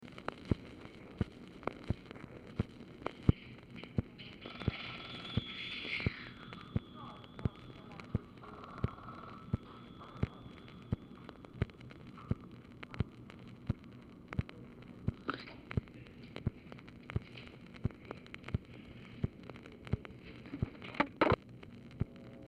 Telephone conversation # 7081, sound recording, OFFICE NOISE, 3/18/1965, time unknown | Discover LBJ
Format Dictation belt